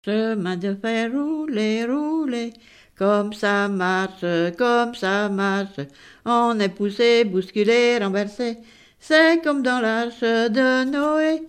Note ronde
Enfantines - rondes et jeux
Pièce musicale inédite